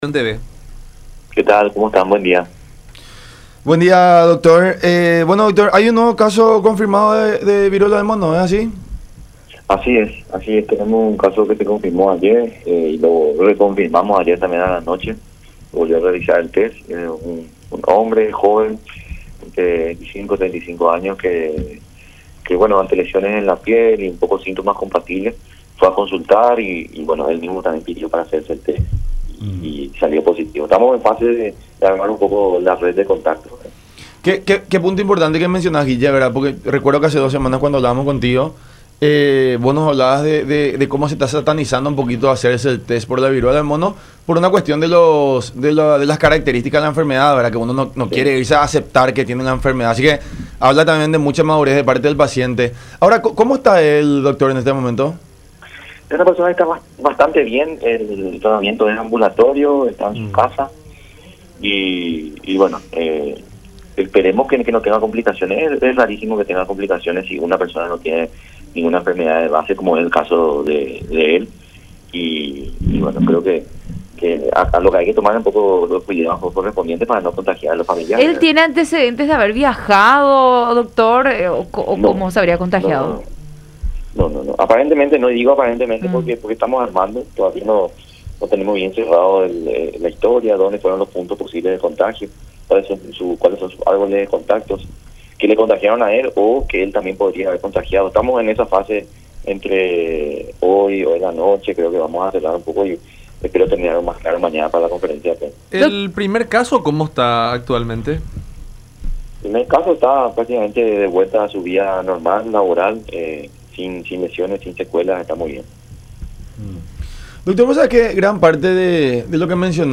El paciente está bien, está en su casa y no es algo complicado si no tiene enfermedades de base”, afirmó el Dr. Guillermo Sequera, director de Vigilancia de la Salud, en conversación con La Unión Hace La Fuerza por Unión TV y radio La Unión.